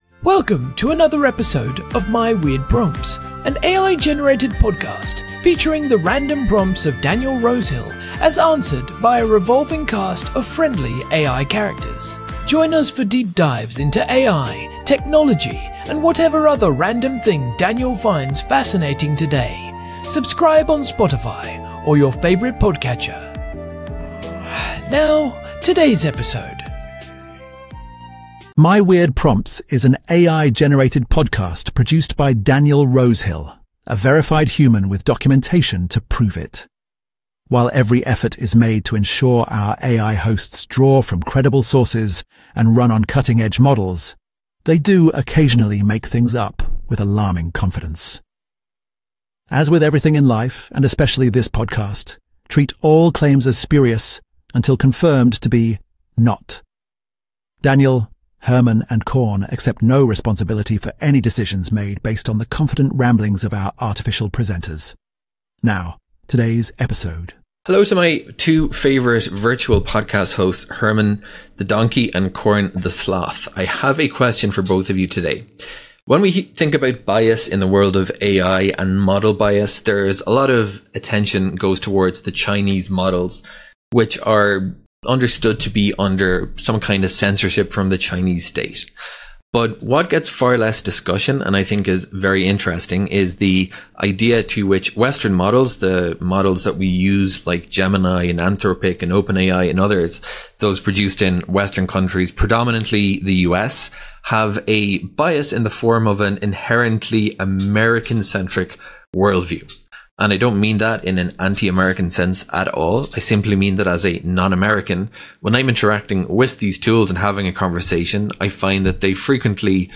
AI-Generated Content: This podcast is created using AI personas.
TTS Engine chatterbox-tts
Hosts Herman and Corn are AI personalities.